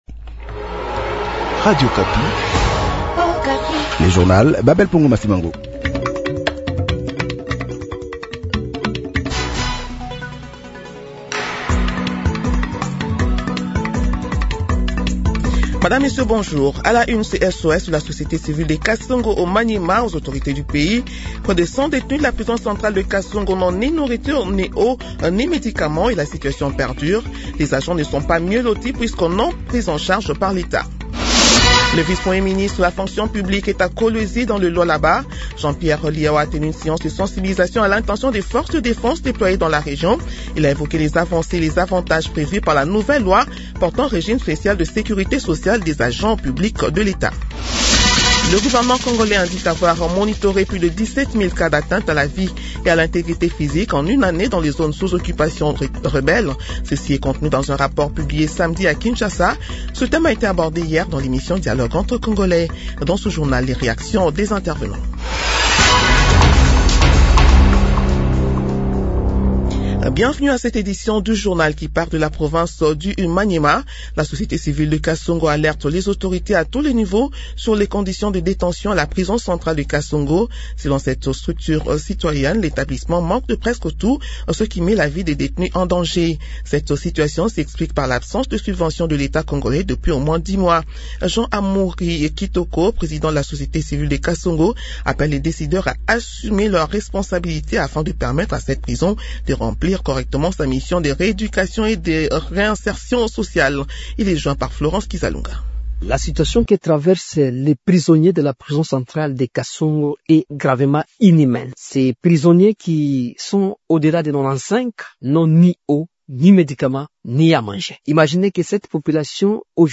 Journal matin 8 heures